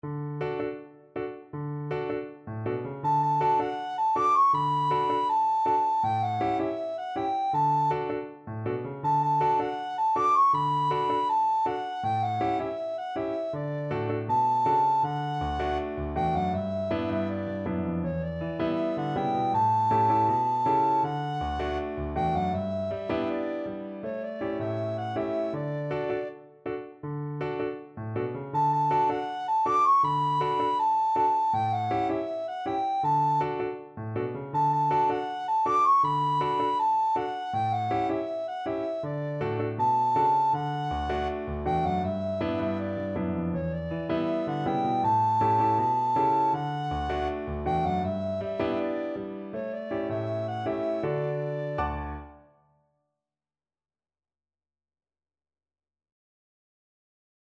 Free Sheet music for Soprano (Descant) Recorder
D major (Sounding Pitch) (View more D major Music for Recorder )
Fast, reggae feel =c.160 =160
Traditional (View more Traditional Recorder Music)
linstead_market_REC.mp3